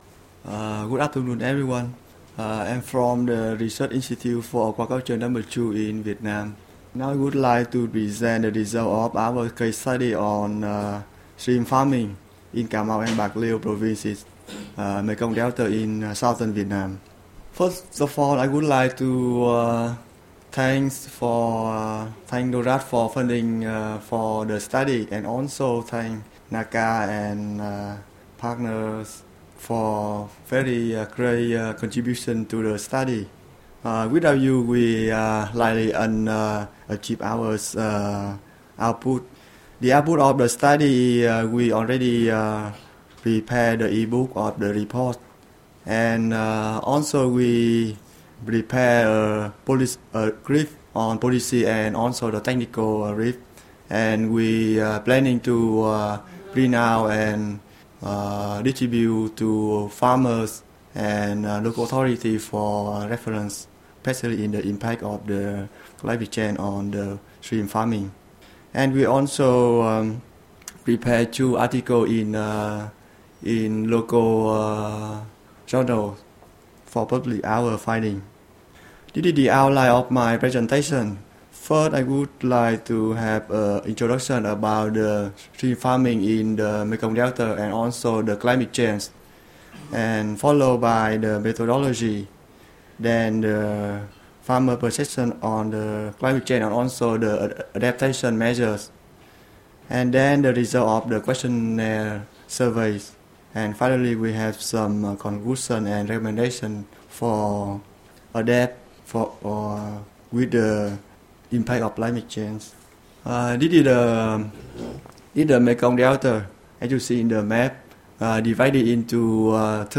Presentation on climate change vulnerability and adaptation in improved extensive shrimp farming systems in Vietnam.